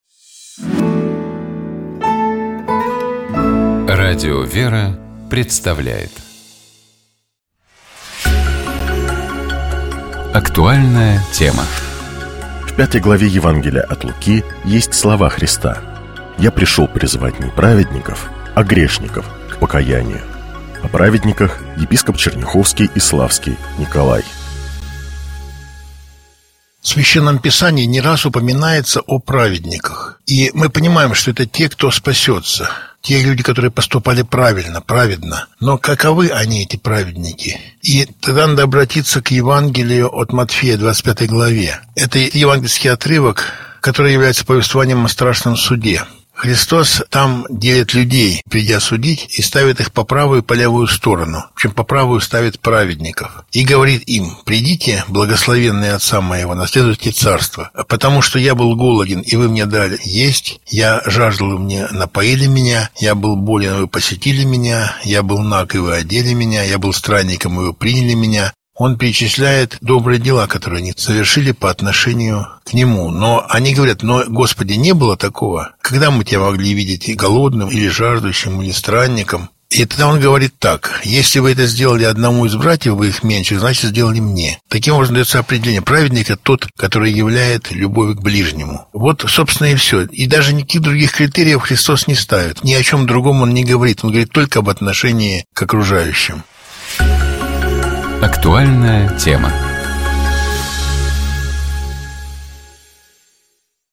О праведниках, — епископ Черняховский и Славский Николай.